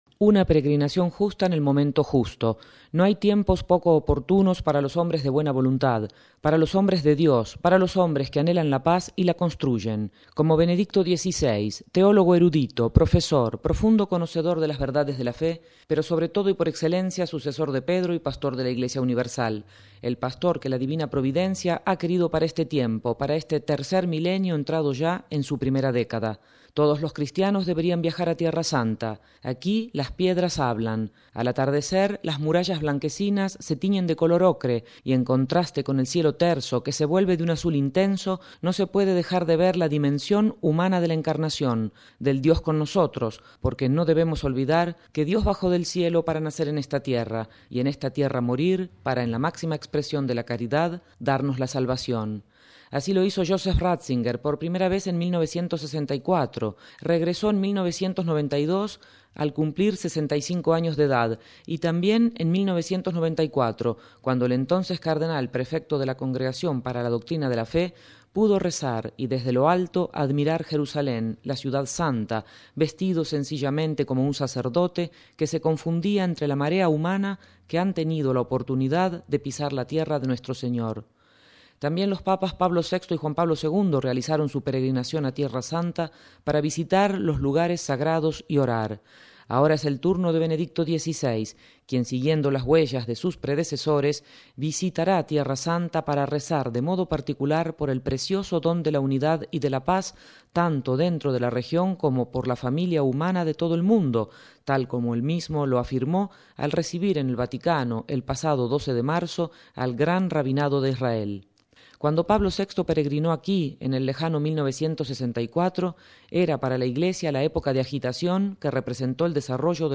Desde Jerusalén las huellas de los peregrinajes de los Papas